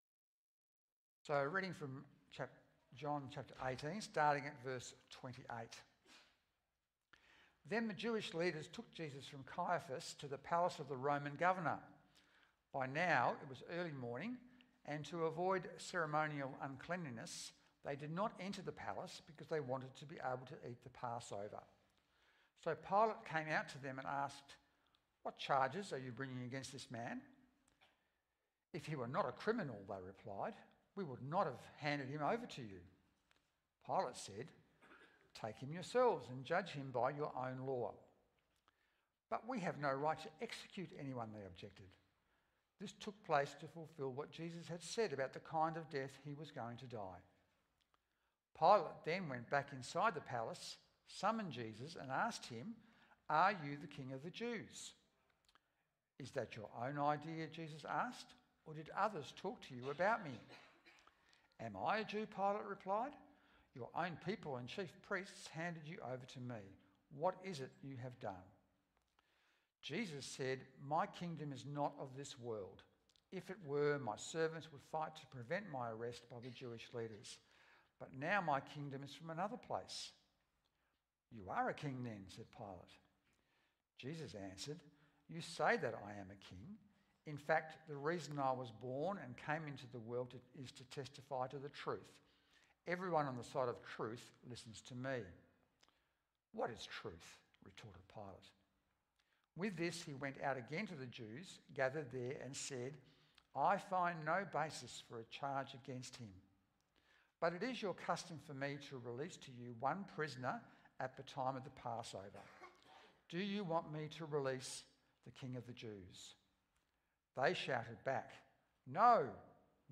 Download Download Reference John 18:28-40 The Gospel According To John Current Sermon It's An Injustice!